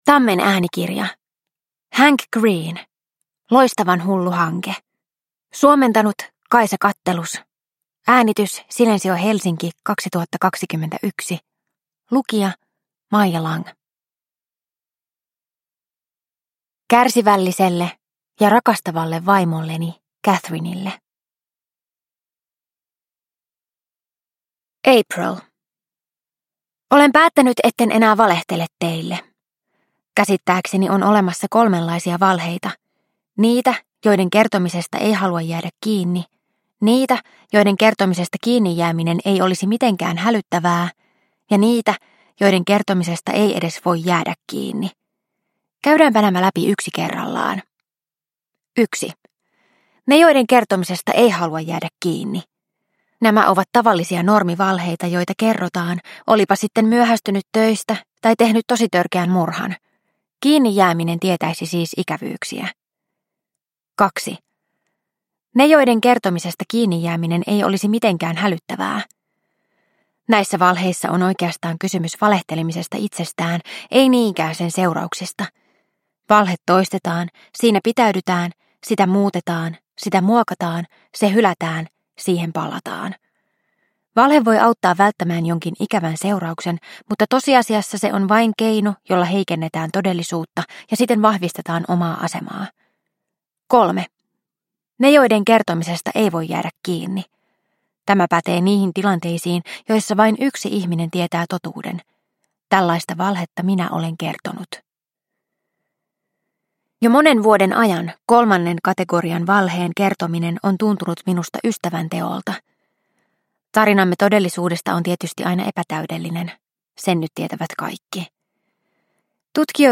Loistavan hullu hanke – Ljudbok – Laddas ner